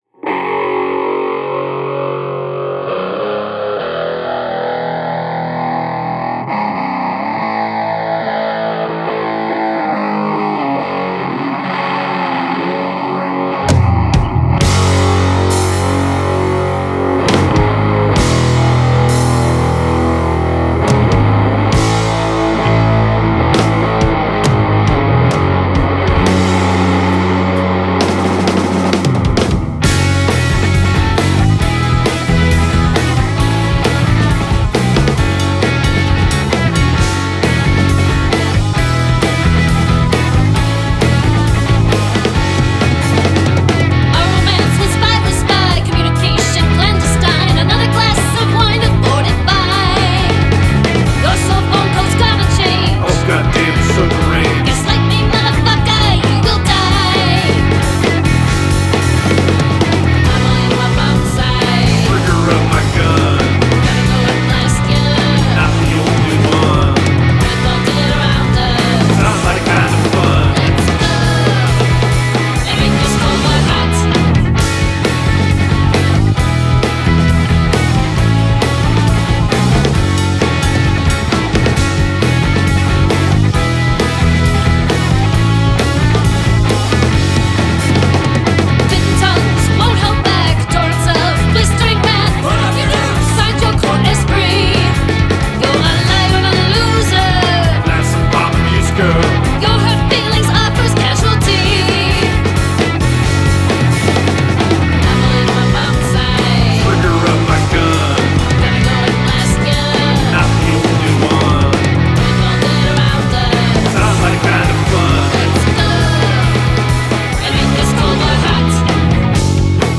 Denver's Pixie Punk Rock Band